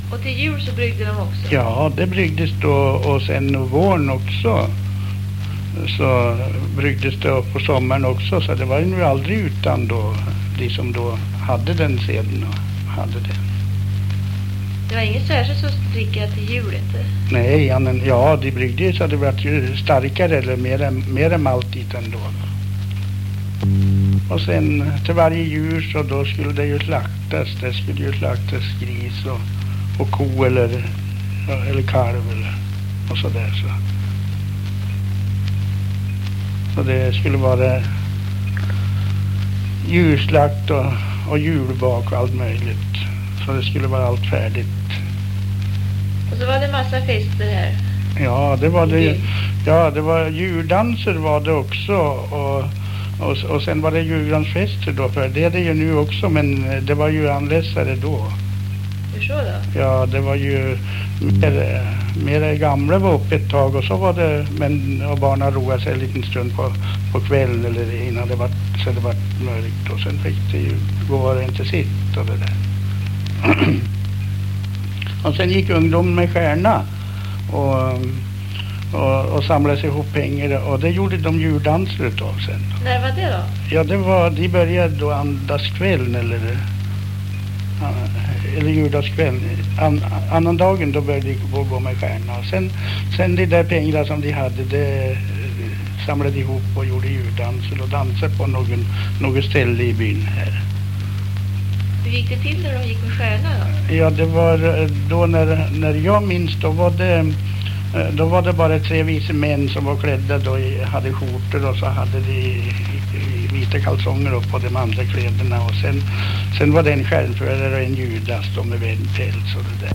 Två moraprofiler ljudupptagning.
Grundmaterialet är ett par vanliga ljudkassetter med tillhörande brus….